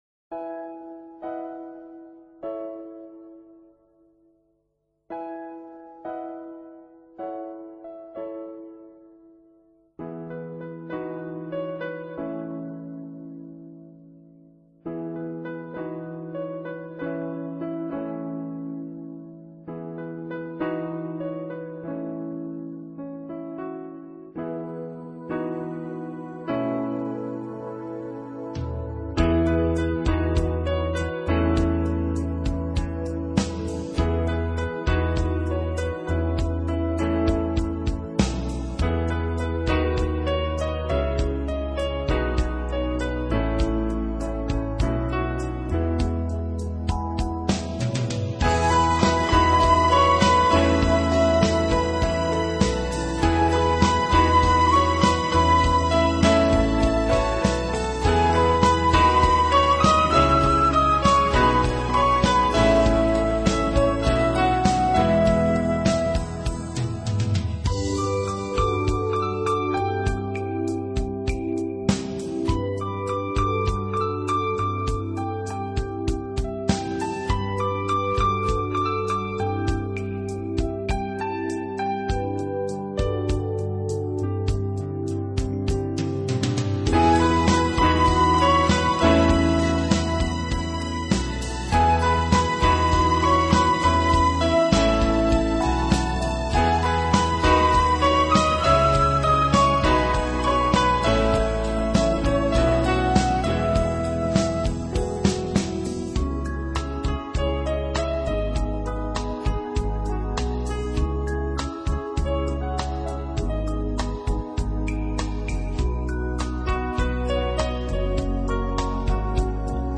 【钢琴纯乐】
传统的笛声，产生非常入耳的旋律，并带出一丝丝温暖的怀旧感觉。